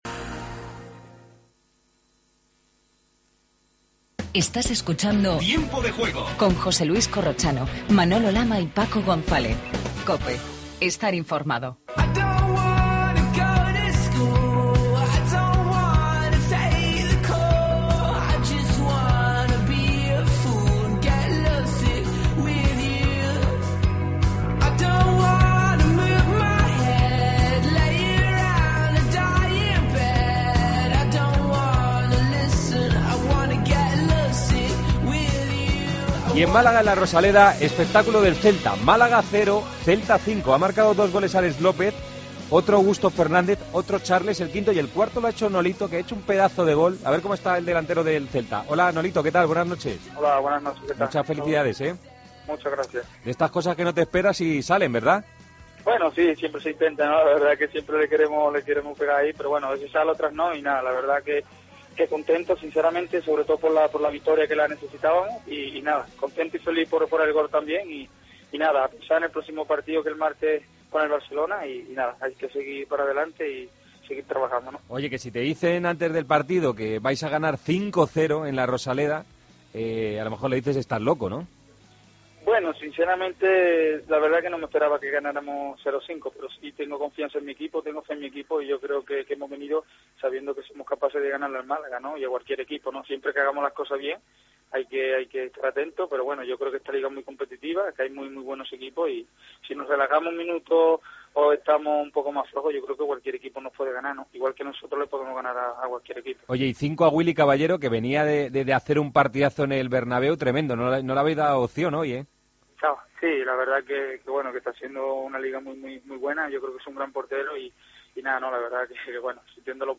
Redacción digital Madrid - Publicado el 27 oct 2013, 02:49 - Actualizado 02 feb 2023, 00:22 1 min lectura Descargar Facebook Twitter Whatsapp Telegram Enviar por email Copiar enlace Resto de partidos de la jornada del sábado: Málaga-Celta, Elche-Granada y Levante-Espanyol. Previas de los partidos del domingo. Entrevistas a Nolito e Iturra.